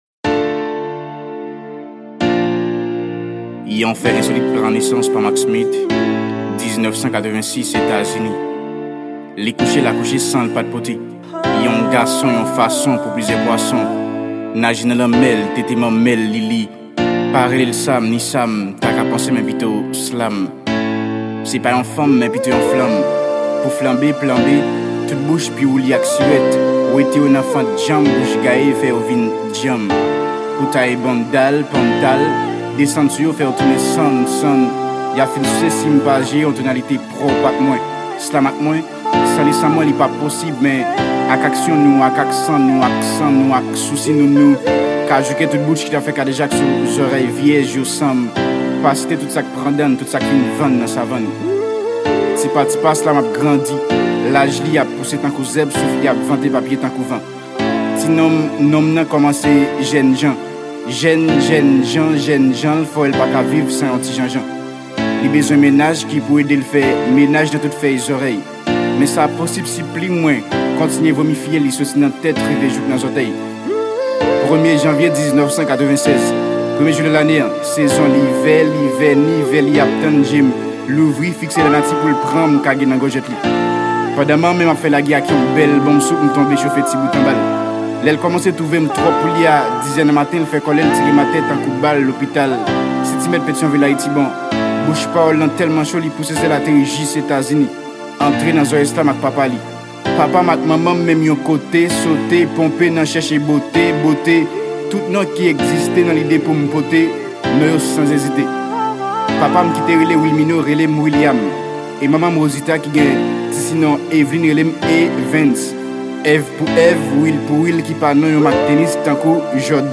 Genre : Slam